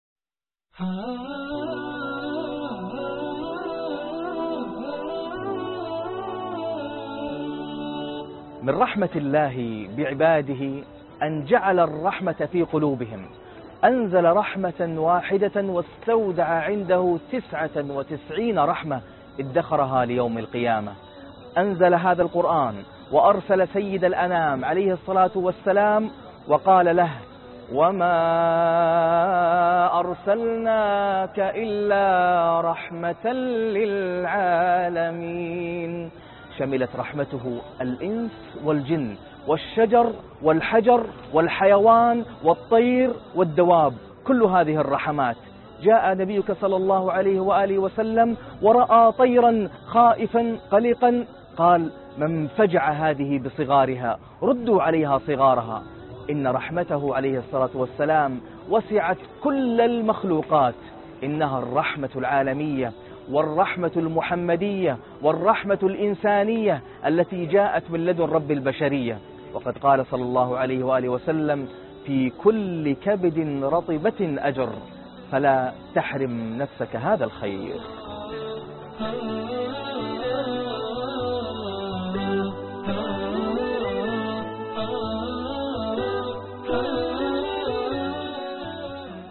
الرحمة المحمدية (21/6/2015) تغريدات قرآنية 2 - القاريء ناصر القطامي